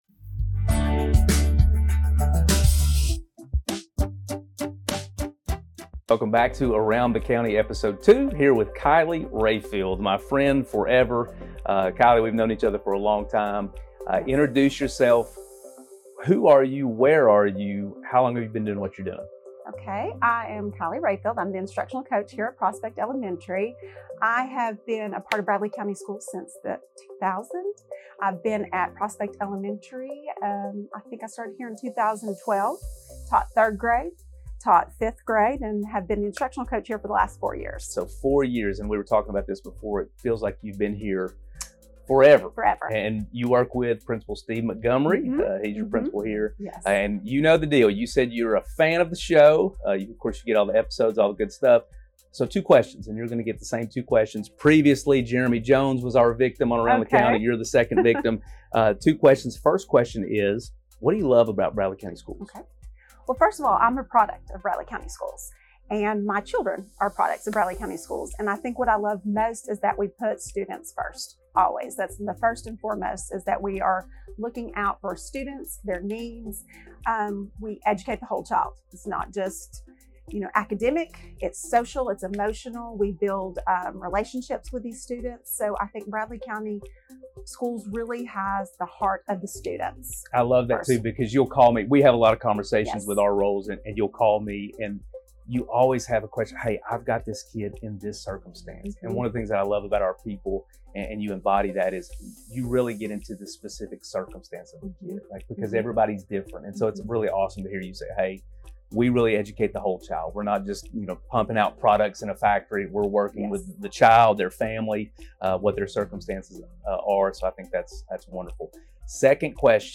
Around the County - Bradley County Schools News and Notes